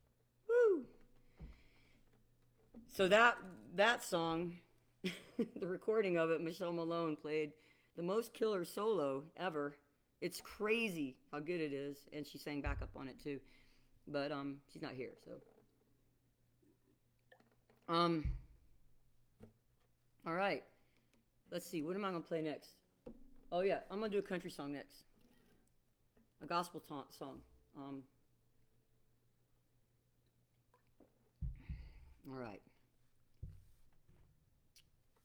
(captured from the facebook live video stream)
10. talking with the crowd (0:35)